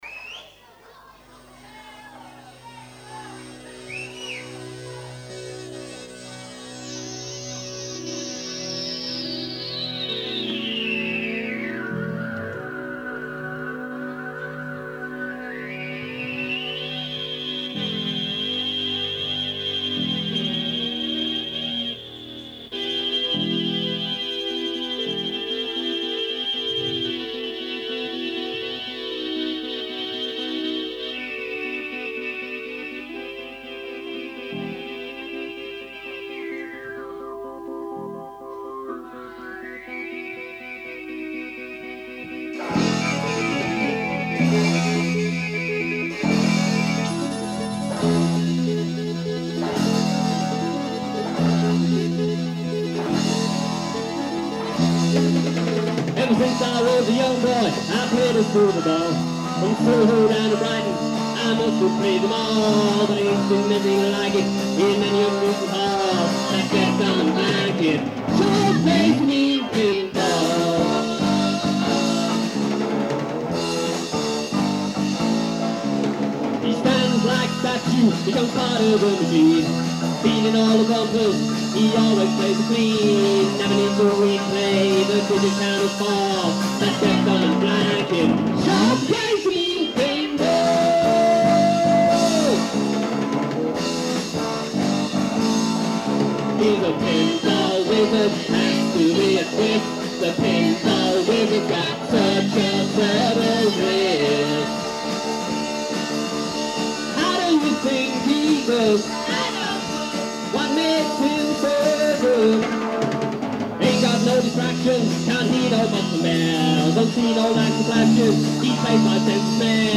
Here are the recordings from two concerts performed at Horndean School.
The first is from July 12th 1983 in the assembly hall.